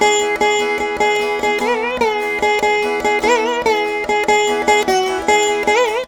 148A VEENA.wav